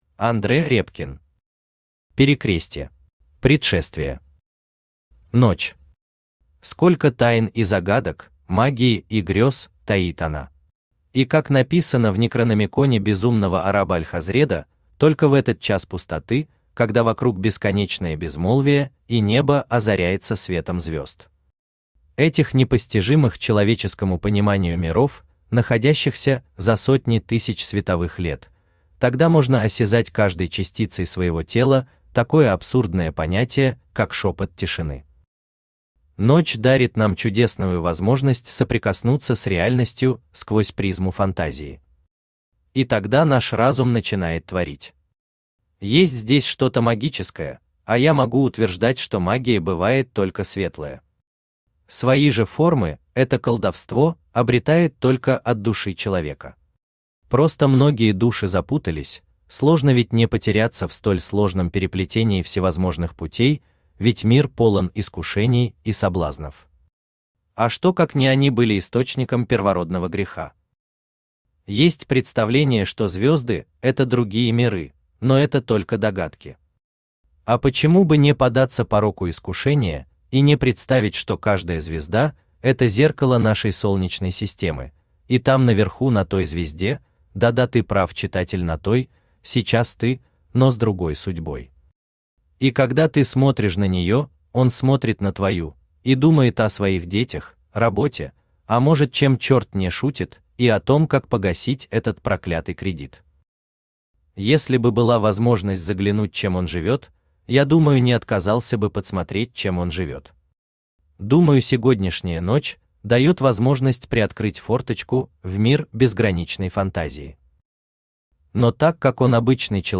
Aудиокнига Перекрестье Автор Андре Репкин Читает аудиокнигу Авточтец ЛитРес.